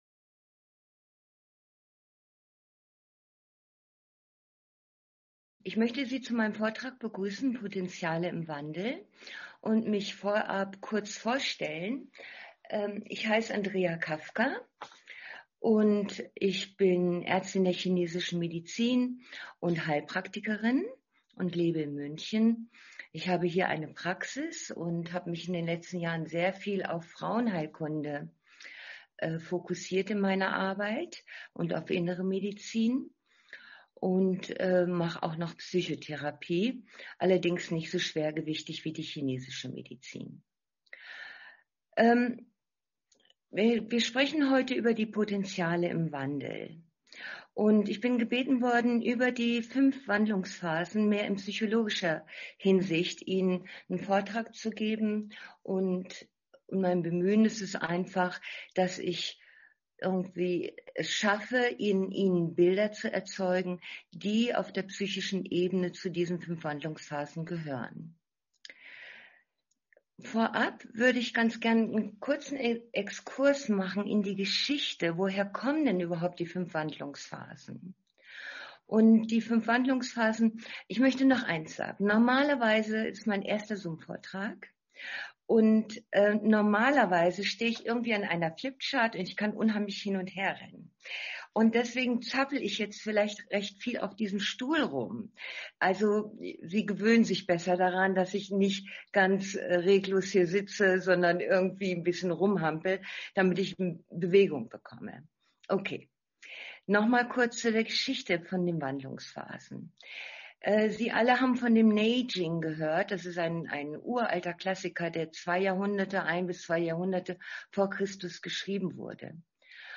Vortrag im Rahmen der 28. Österreichischen Qigong Tage